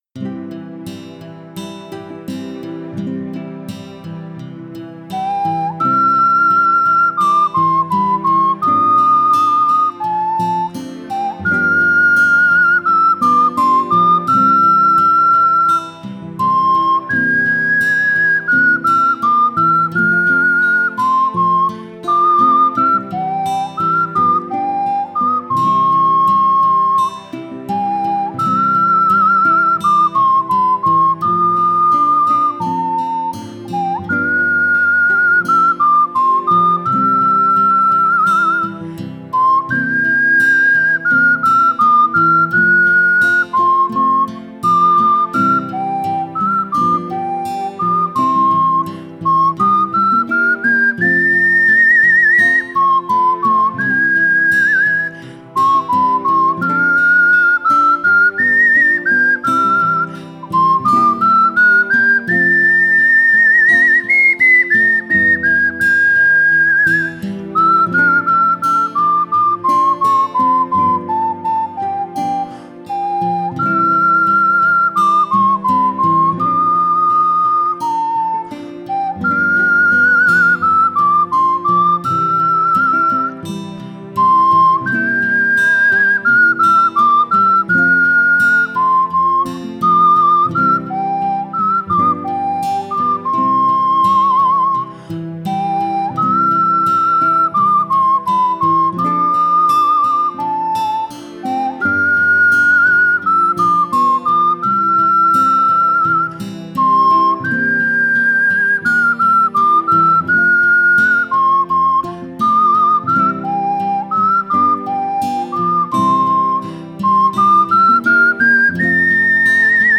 鼻笛練習用音楽素材
鼻笛教室等で使用の練習用素材はこちらにまとめます。
北の国から　伴奏　サンプル演奏付